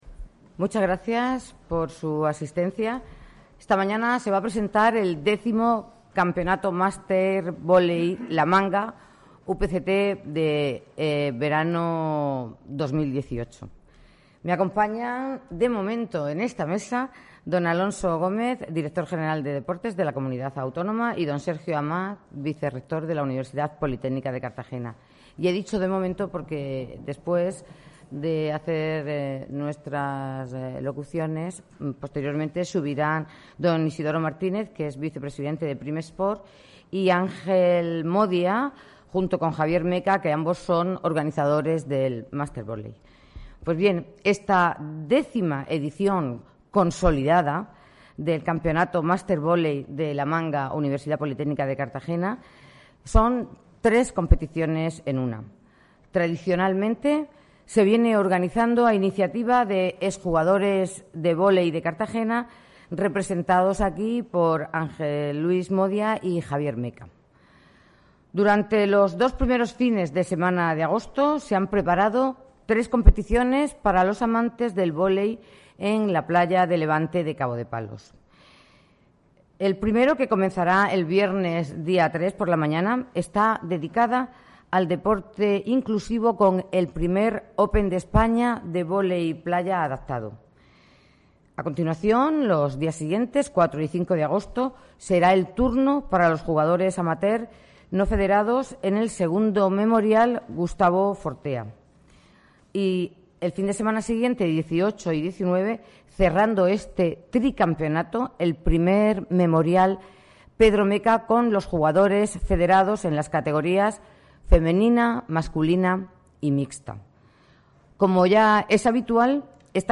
Audio: Presentaci�n del X Mastervoley-UPCT La Manga 2018 (MP3 - 8,79 MB)